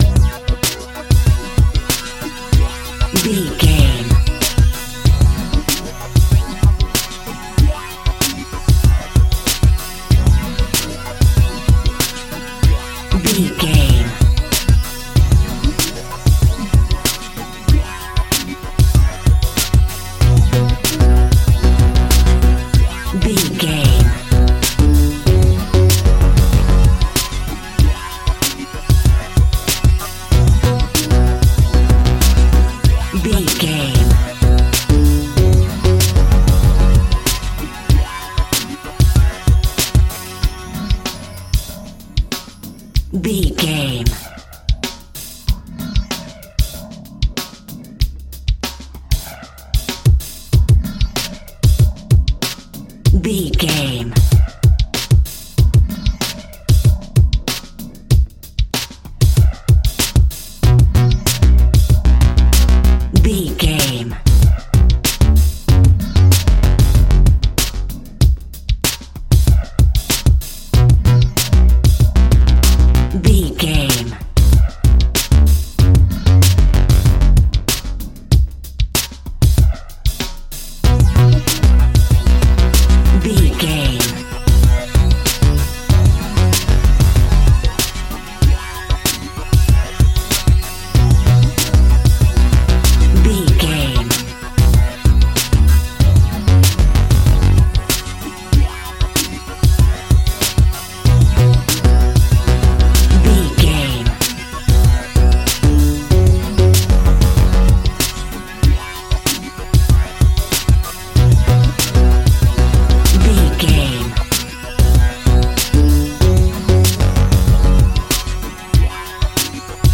In-crescendo
Aeolian/Minor
hip hop
hip hop instrumentals
funky
groovy
dirty hip hop
east coast hip hop
electronic drums
synth lead
synth bass